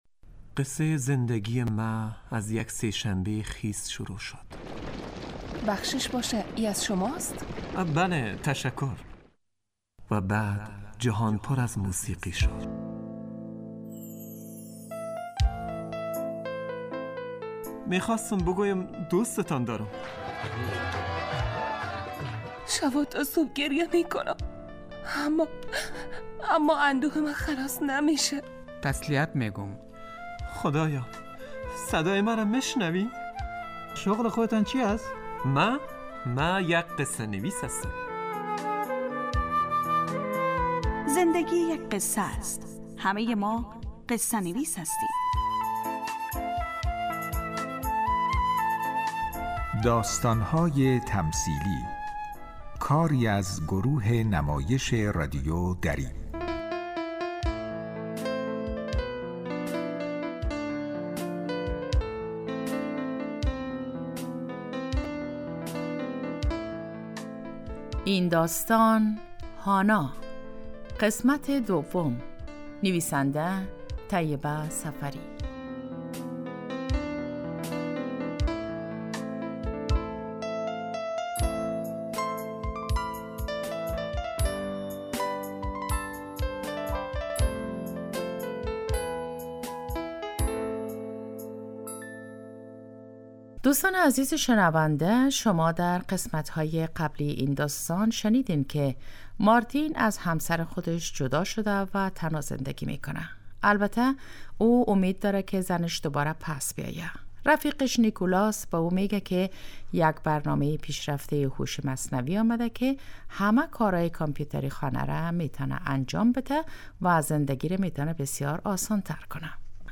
داستان های تمثیلی یک برنامه 15 دقیقه ای در قالب نمایش رادیویی می باشد که همه روزه به جز جمعه ها از رادیو دری پخش می شود موضوع اکثر این نمایش ها پرداختن به...